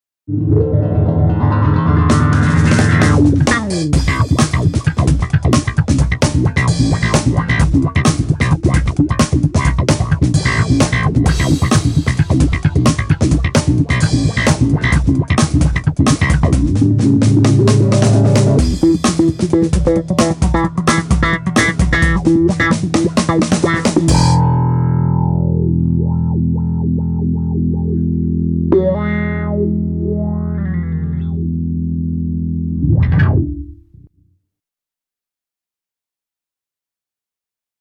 BOSS PW-10 V-Wah gitarov� efekt